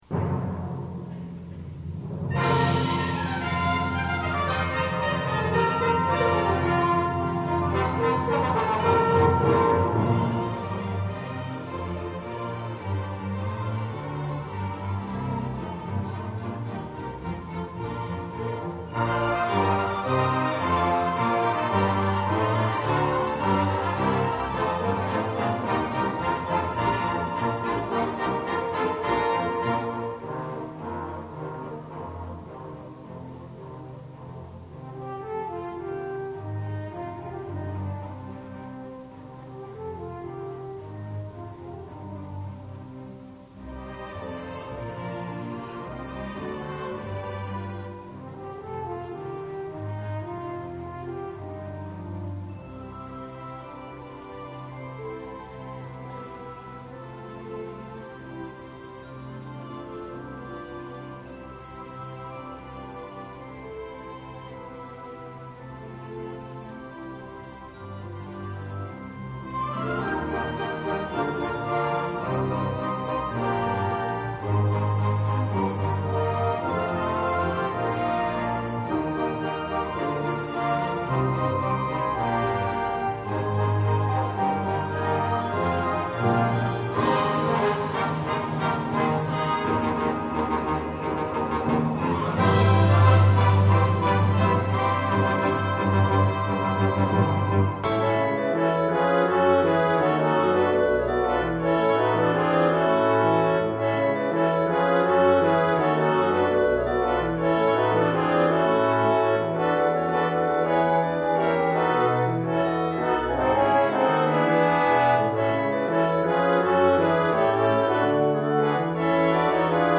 Instrumentation: 1111-1110-t+1, pno, str
Ensemble: Chamber Orchestra